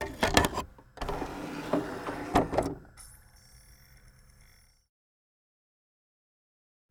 На этой странице собраны звуки магнитофона: шум ленты, щелчки кнопок, запись с кассет.
Шум магнитофона